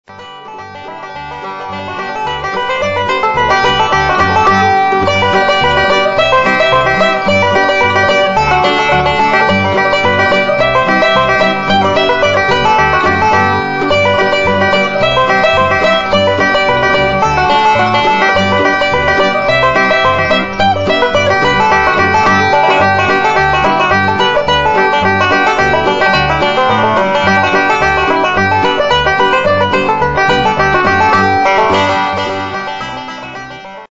5-String Banjo .mp3 Samples
5-string Banjo Samples - Advanced Level